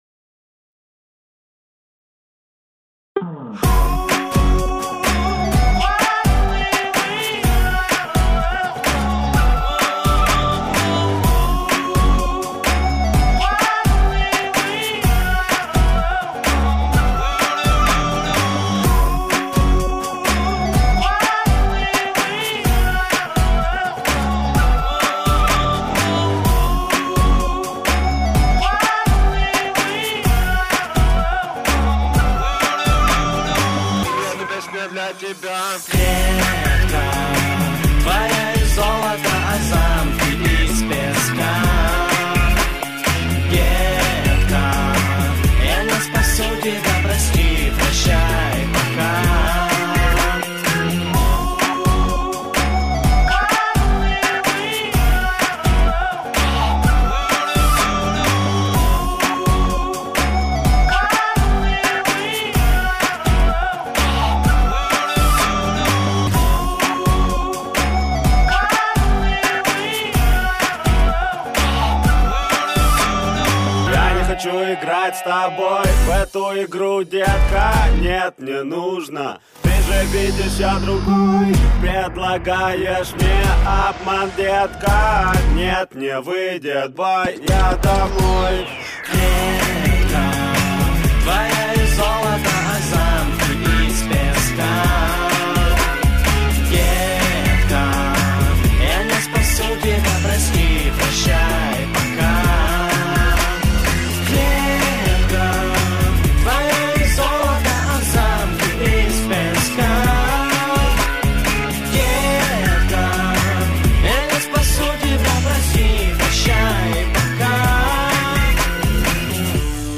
минусовка версия 207590